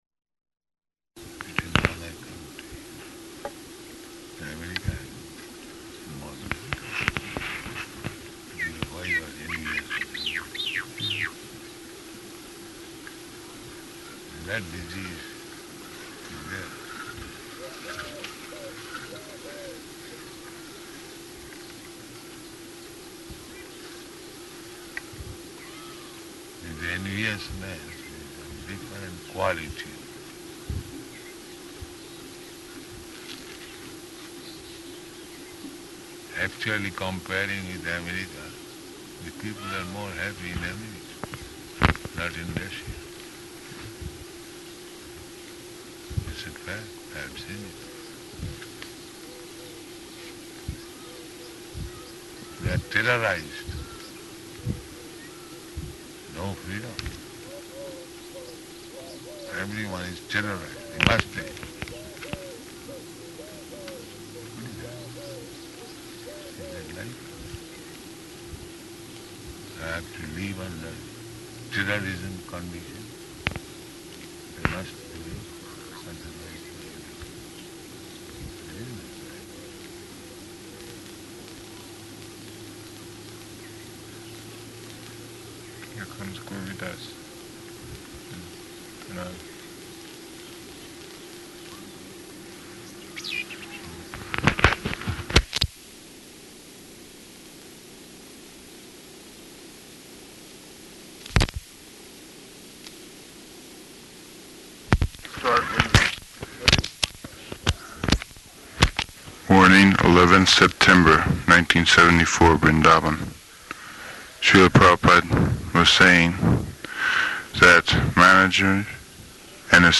Garden Conversation
Garden Conversation --:-- --:-- Type: Conversation Dated: September 11th 1974 Location: Vṛndāvana Audio file: 740911GC.VRN.mp3 Prabhupāda: It will go on like that.